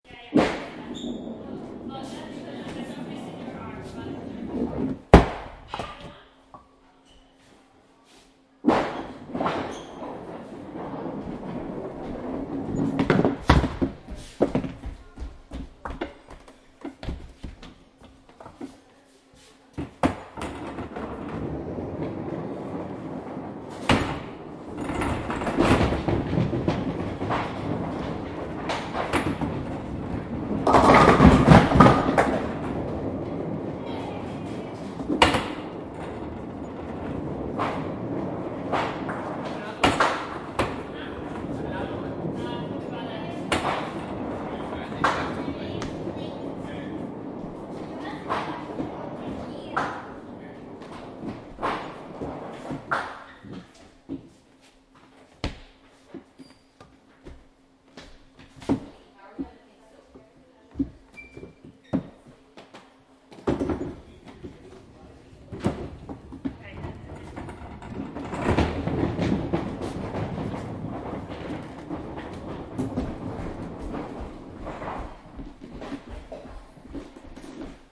Download Bowling sound effect for free.
Bowling